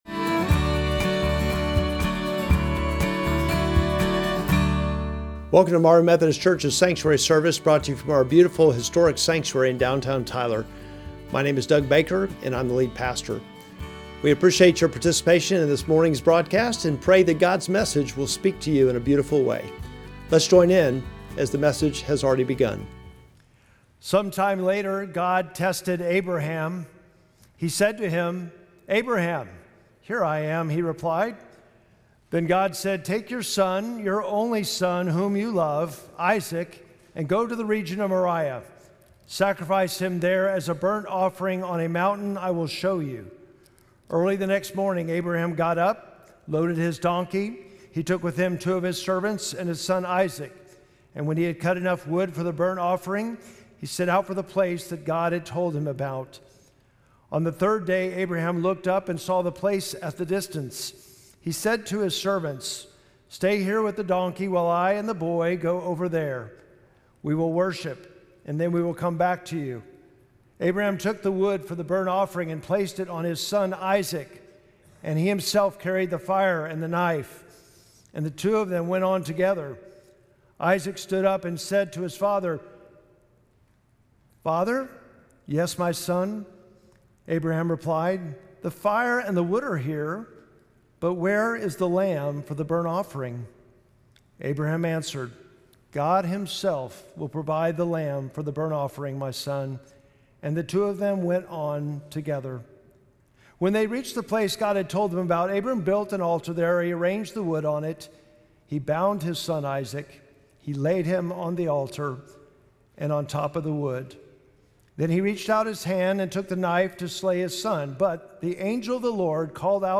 Sermon text: Genesis 22:1-14